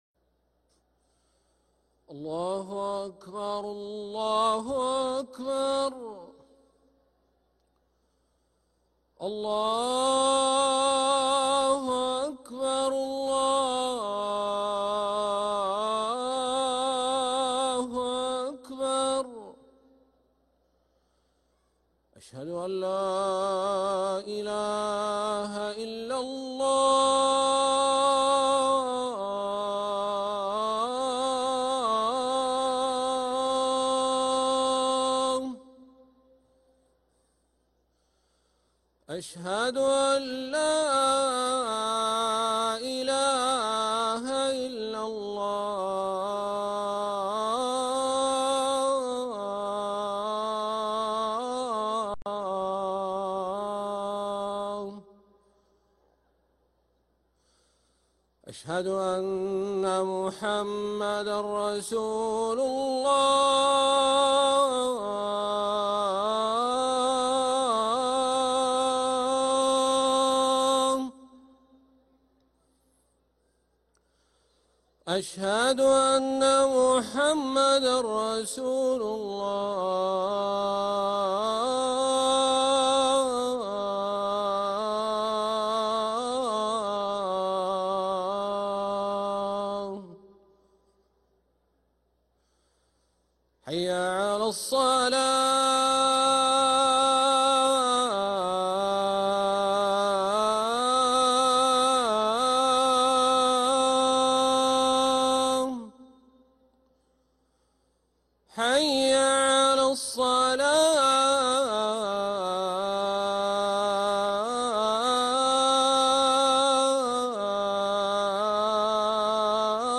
ركن الأذان 🕋